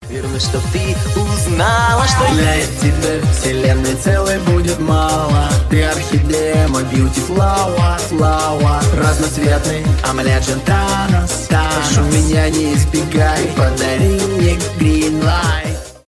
евродэнс
поп
ремиксы